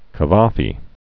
(kə-väfē), Constantine Peter Originally Konstantínos Pétrou Kaváfis. 1863-1933.